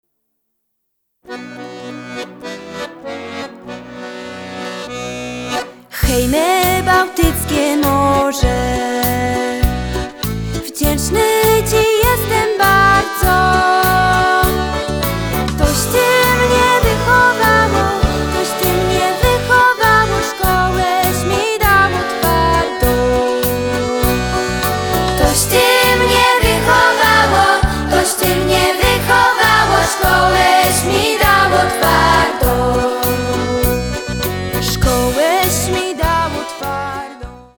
Podkład muzyczny akordeonowy - studyjny.